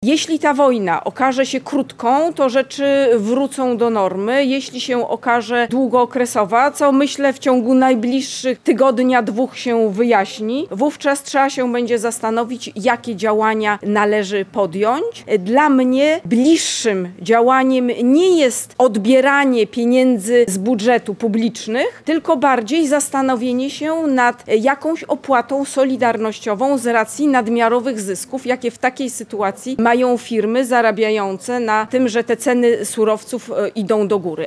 Minister Katarzyna Pełczyńska-Nałęcz odniosła się podczas wizyty w Kraśniku do pojawiających się pomysłów obniżenia VAT i akcyzy na paliwo w związku z rosnącymi cenami tych surowców.